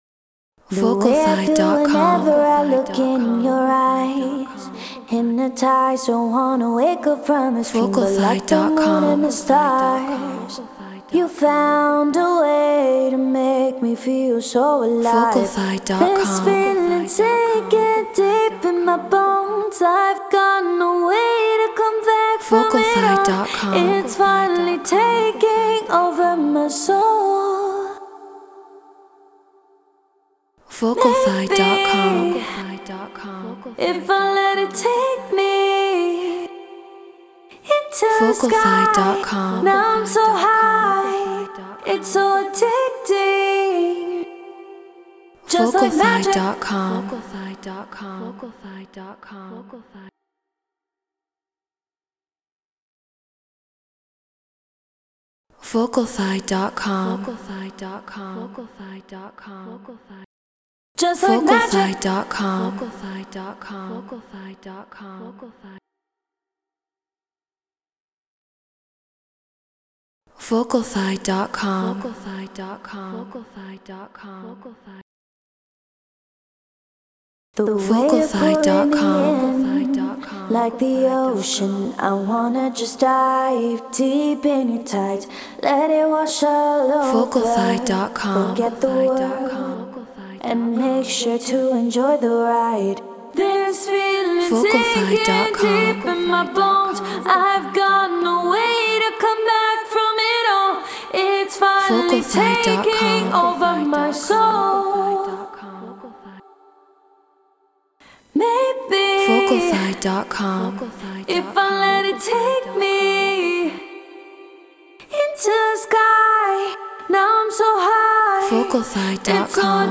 Get Royalty Free Vocals.
Non-Exclusive Vocal.